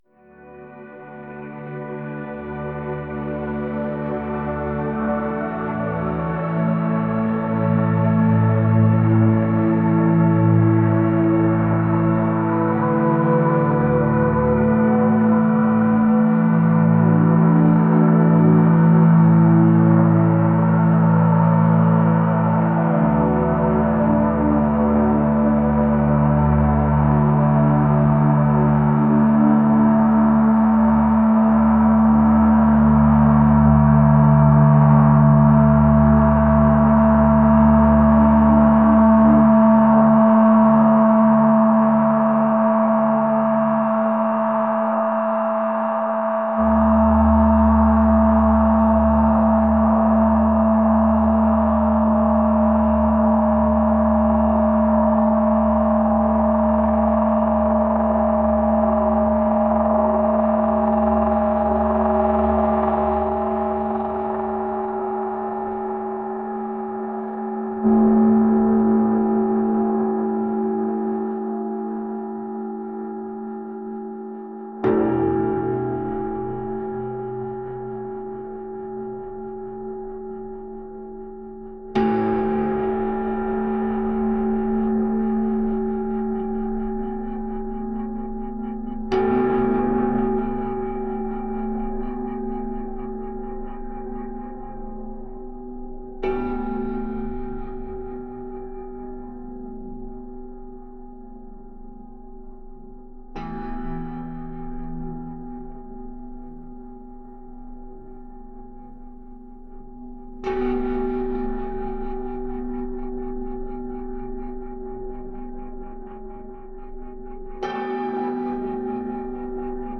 pop | atmospheric | ethereal